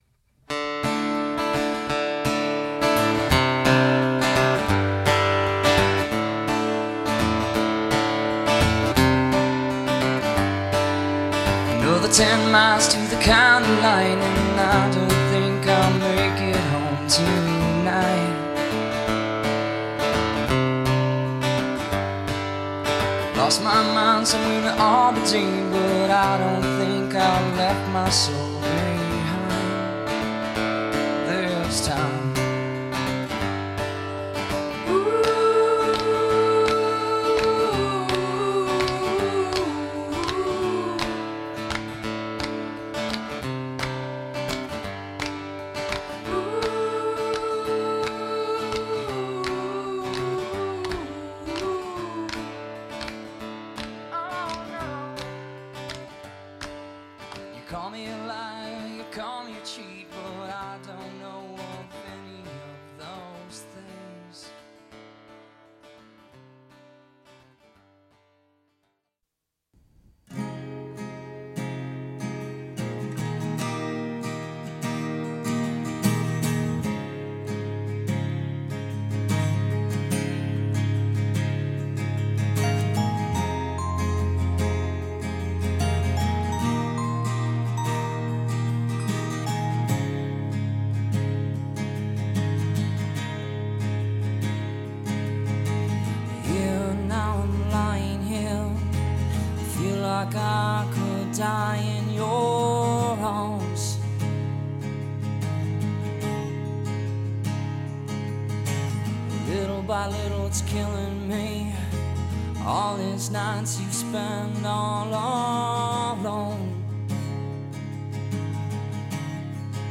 a singer/songwriter from the heartland
This is a great collection of Indie-rock!